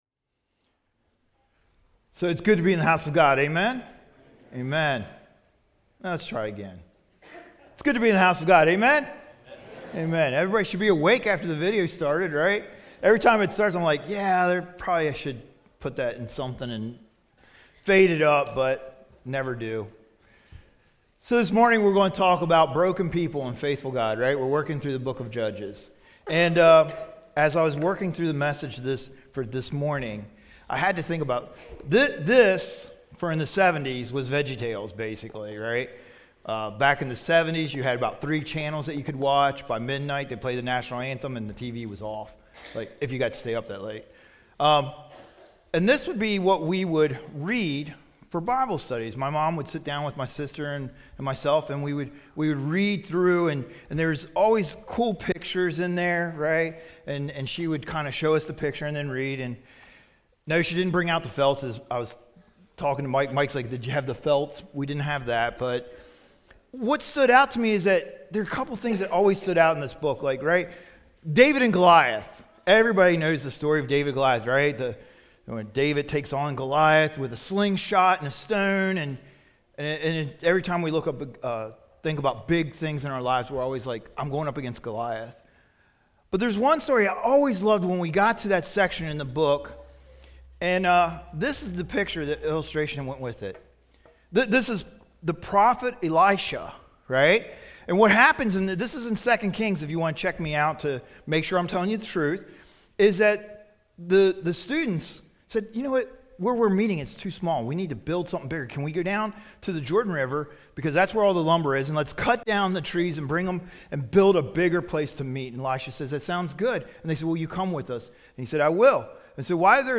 Tagged with 2nd Service Audio (MP3) Previous When Ambition Replaces a Calling Next Chosen by God, Controlled by Flesh 0 Comments Add a Comment Cancel Your email address will not be published.